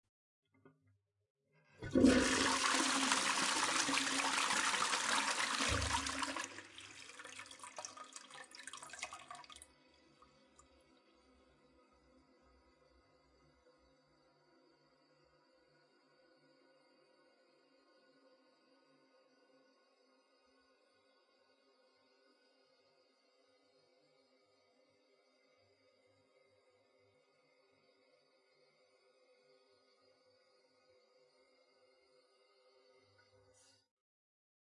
Leaking toilet
描述：Broken toilet seems to leak a little bit too much :)
标签： plumbing flush flushing water wc bathroom toilet
声道立体声